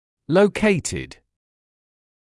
[ləu’keɪtɪd][лоу’кейтид]расположенный